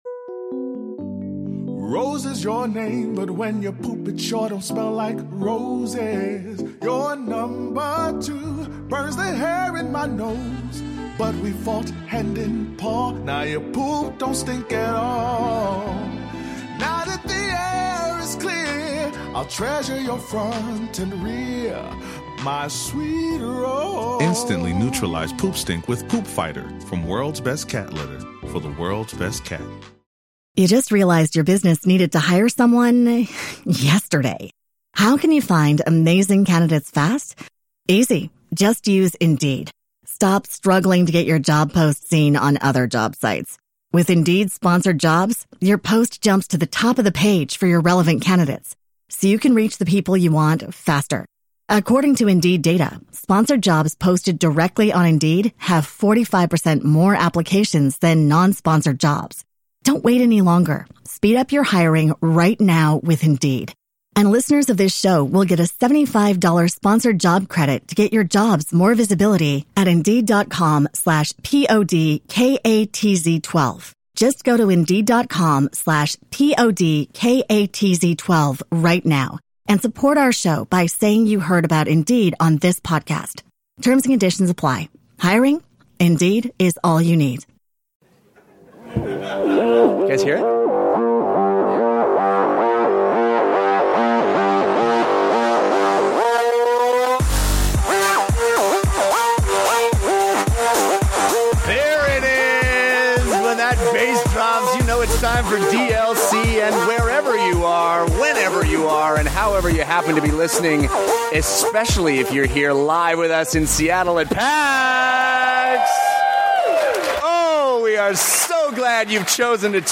This special episode was recorded in front of a LIVE audience at PAX 2015!